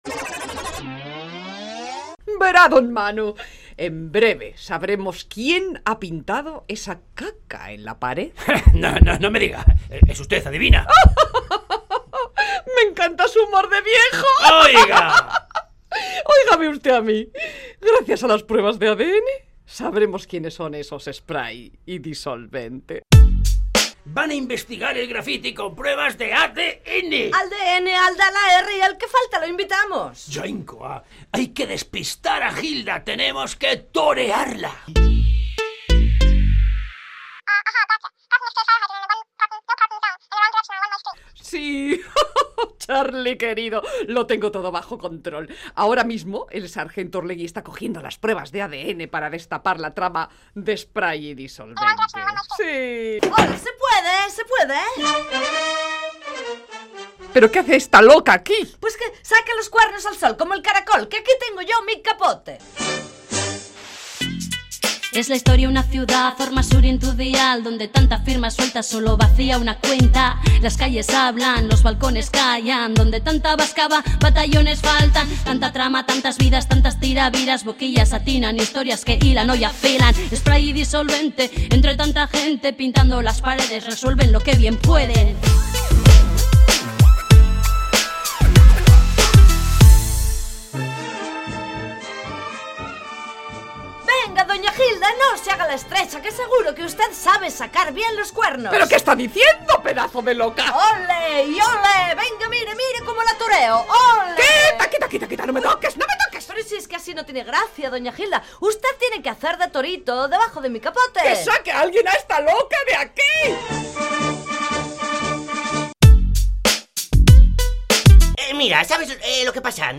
Entrega número 25 de la Radio-Ficción “Spray & Disolvente”